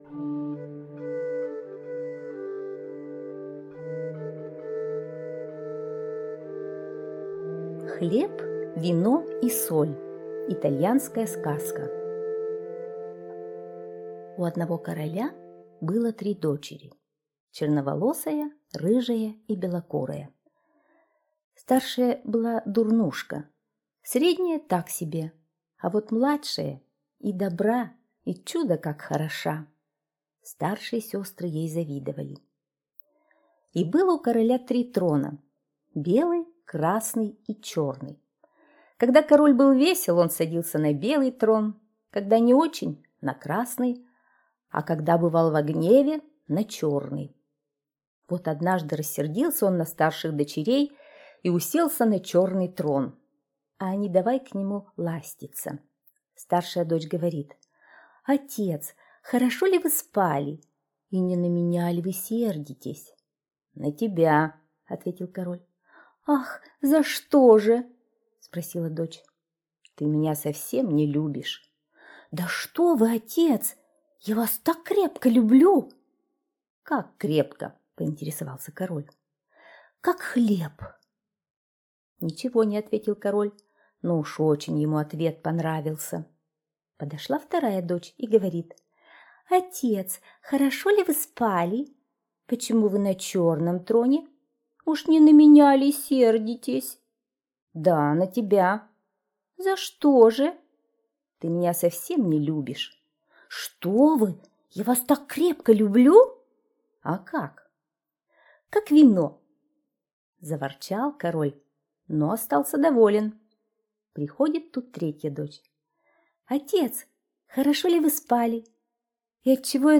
Хлеб, вино и соль - итальянская аудиосказка. Сказка про короля, у которого было три дочери: дурнушка, так себе и чудо как хорошая...